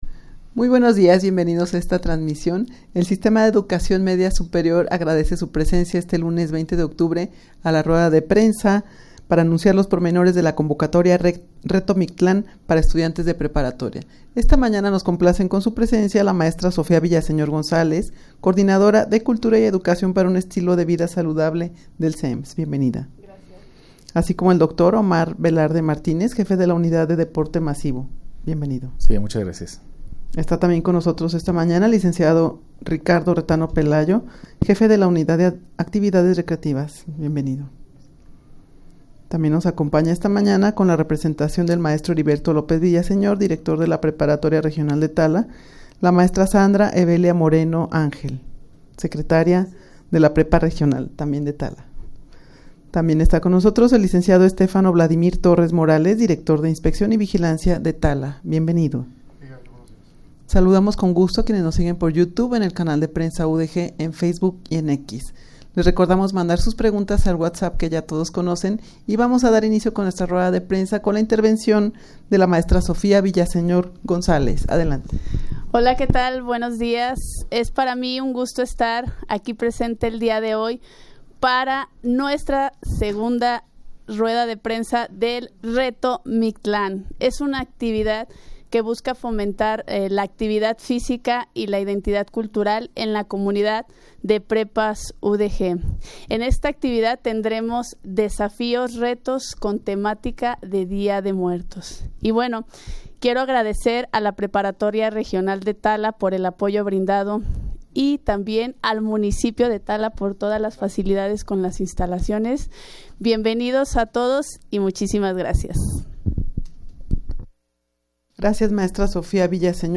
Audio de la Rueda de Prensa
rueda-de-prensa-para-anunciar-los-pormenores-de-la-convocatoria-reto-mictlan.mp3